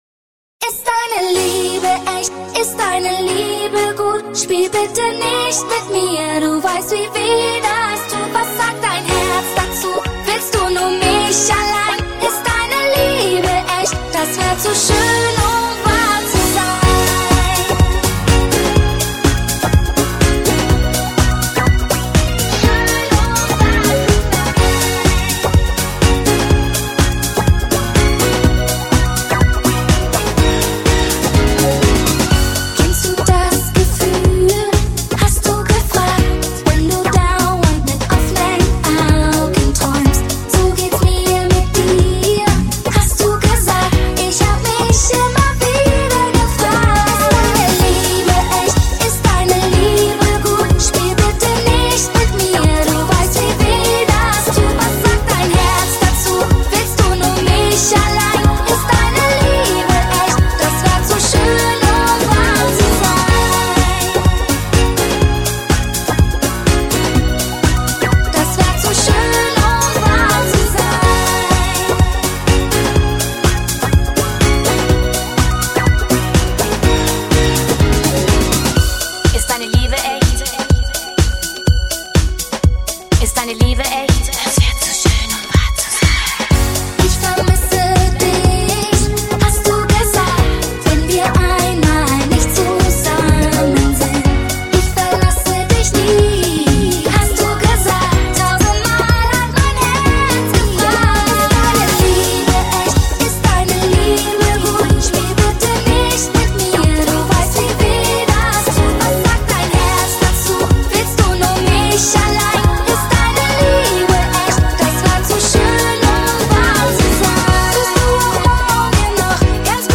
德语歌曲